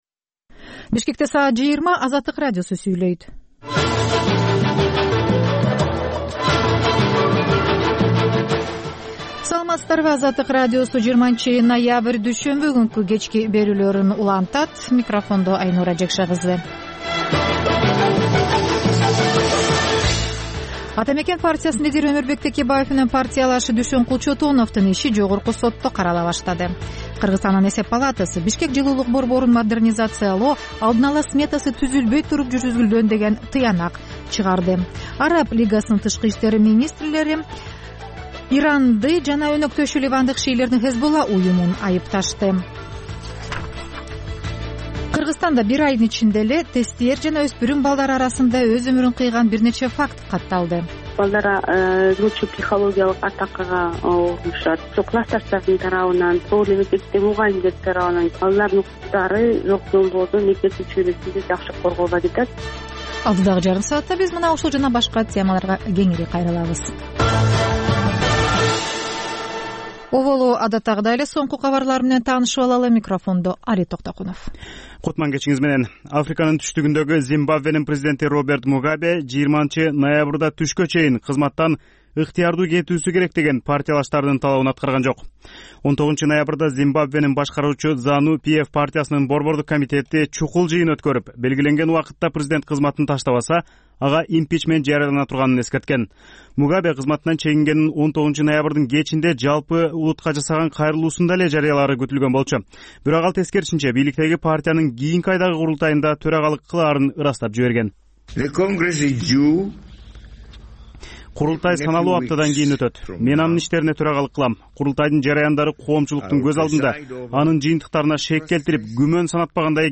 Бул үналгы берүү ар күнү Бишкек убакыты боюнча саат 20:00дан 21:00гө чейин обого түз чыгат.